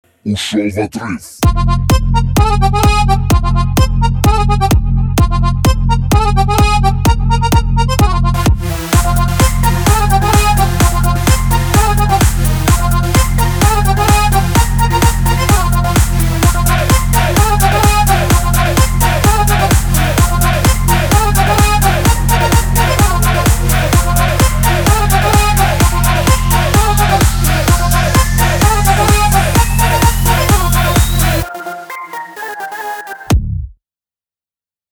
веселые
Электро-шаурма